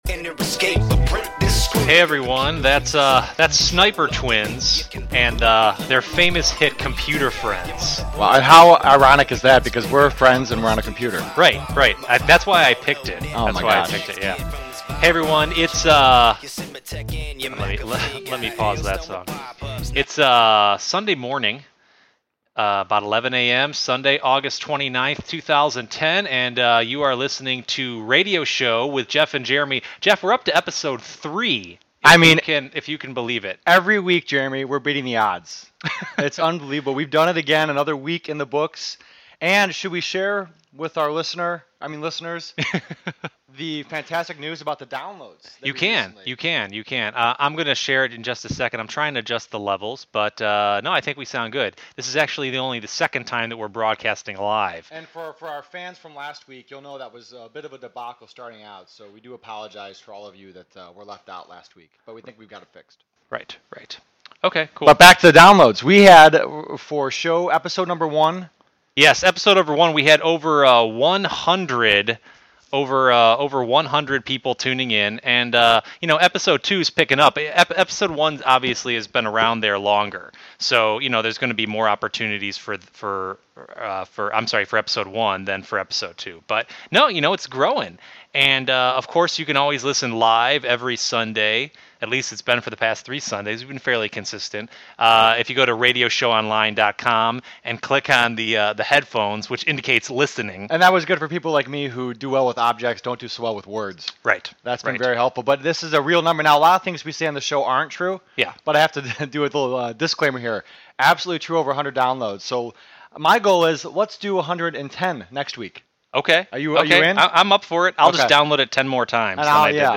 You can download our second podcast in which we’ve taken out all (most) of the mistakes we made to make ourselves seem more professional than we actually are.